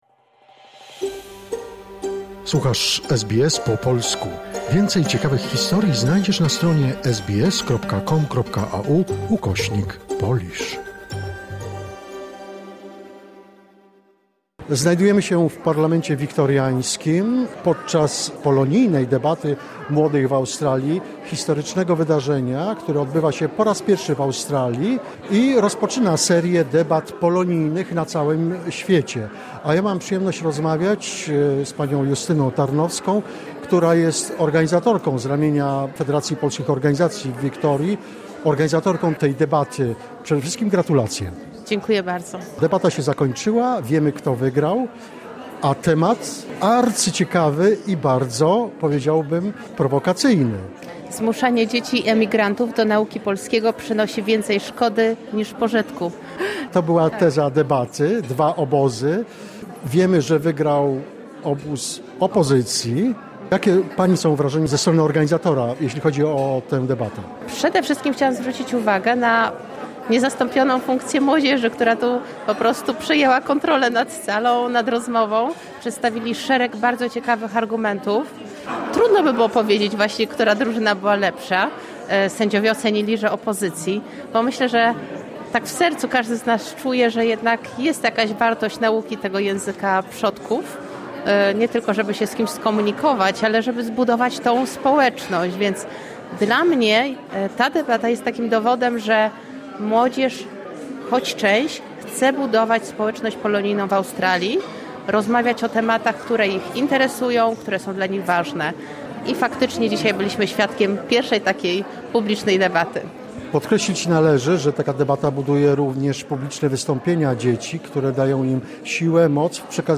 Relacja z Polonijnej Debaty Młodych w Australii, która miała miejsce w gmachu Parlamentu Wiktoriańskiego w Melbourne, 30 września 2025 r. Historyczna debata zorganizowana przez Federacje Polskich Organizacji w Wiktorii miała na celu rozwój kompetencji społecznych, obywatelskich i językowych uczestników, a także promowanie polskiego dziedzictwa kulturowego w środowisku australijskiej Polonii.